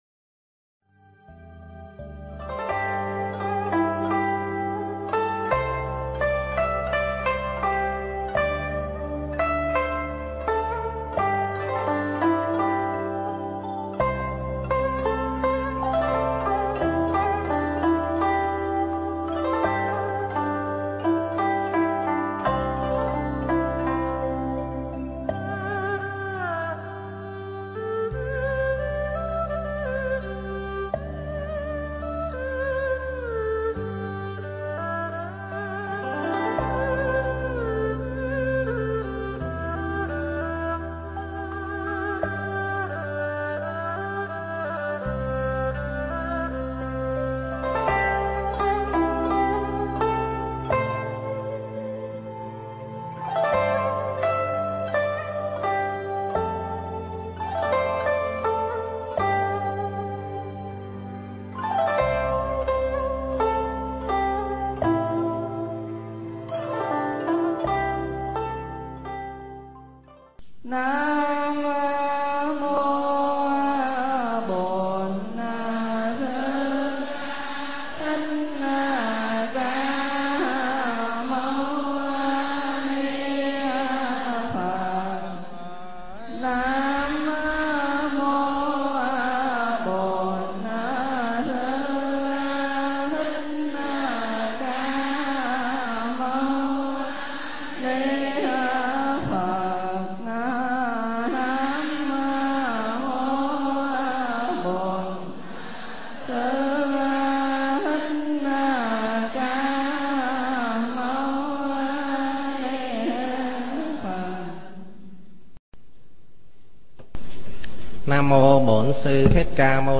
Nghe Mp3 thuyết pháp Sắc Hoa Miền Bắc